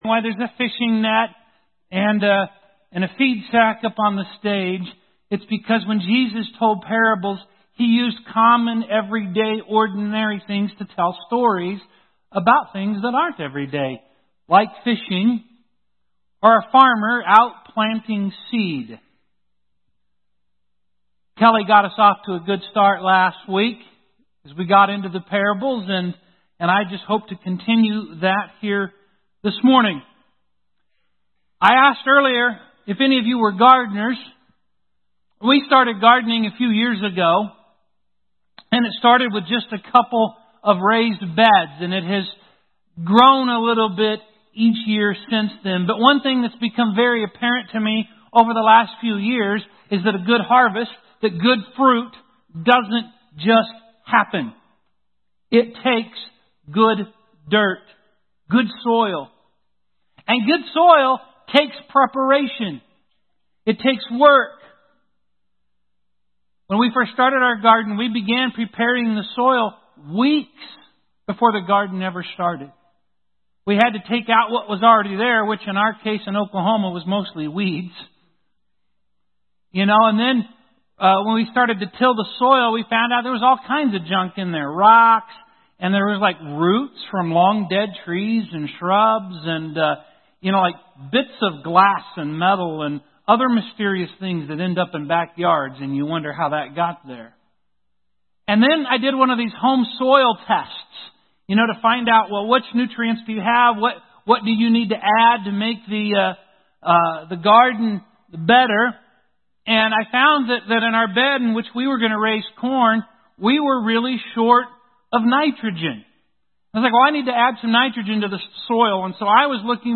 Stories that Changed the World Audio Sermon Save Audio Save PDF This week we continue our exploration of Jesus’ parables with the parable of the Sower found in Luke 8:4-15.